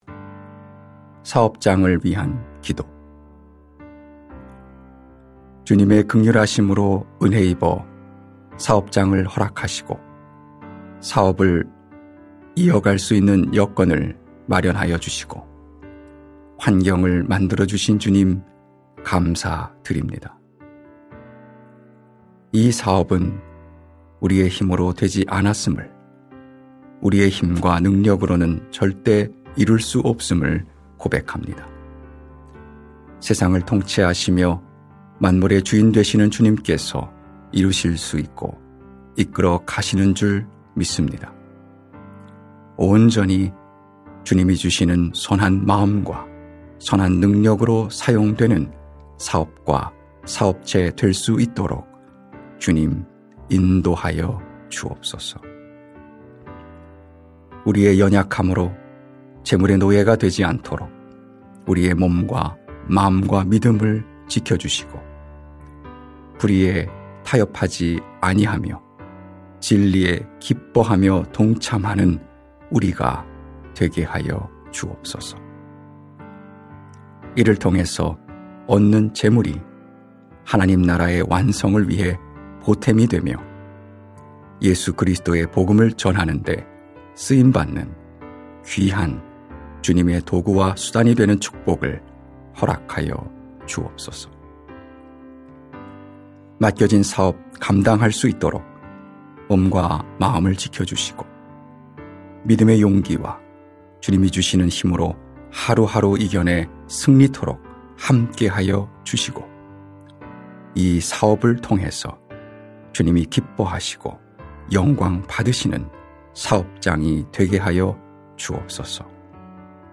중보기도